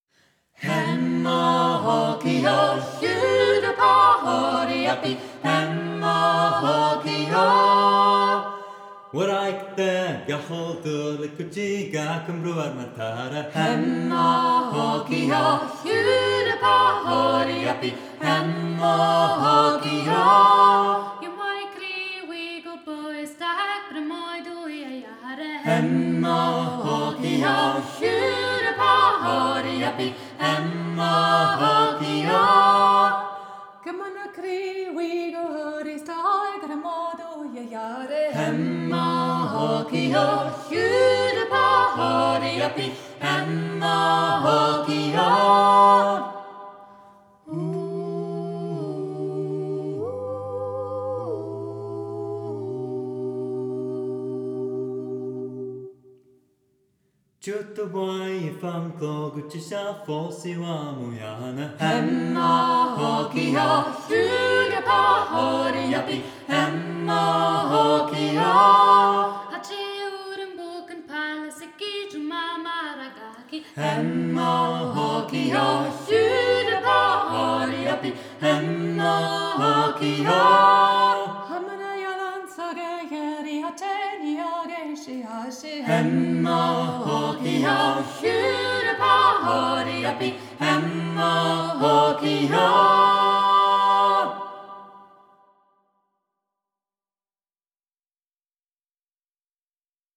eine Familie und ein A Capella-Trio. Wir singen dreistimmige Lieder aus aller Welt, z.B. Griechenland, Frankreich, Georgien, Irland, dem Alpenland u.v.m. Unsere Verbindung untereinander und die Liebe zum Gesang ist für uns selbst immer wieder eine große Freude und wirkt sich bei unseren Konzerten auf den ganzen Raum inklusive unsere Zuhörer aus.
Der Klangcharakter des Offenen Vokalensembles ist direkt, authentisch und lebendig, ausgezeichnet durch innere Verbundenheit und Stimmigkeit.